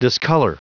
Prononciation du mot discolor en anglais (fichier audio)
Prononciation du mot : discolor